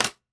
click1.wav